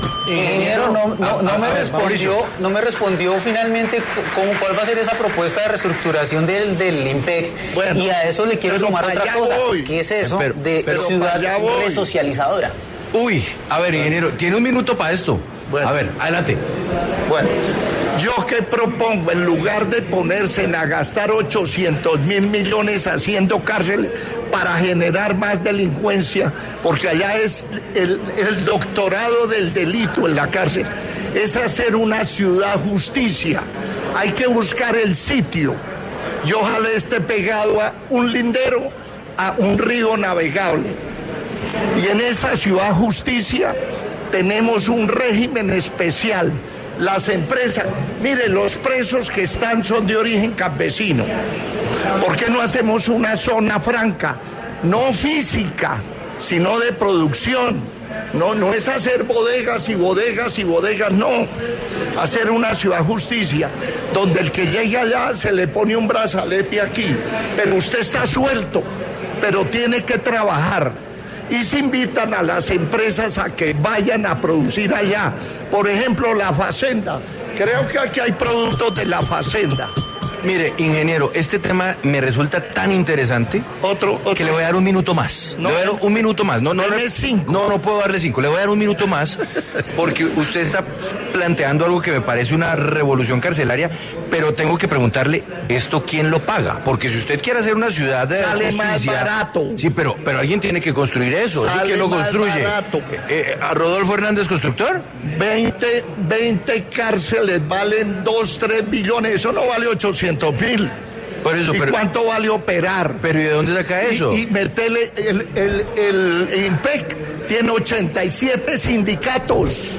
Sigue La W conversó con el candidato presidencial Rodolfo Hernández sobre las propuestas que compondrán un eventual plan de gobierno suyo, de cara a las elecciones del próximo 29 de mayo.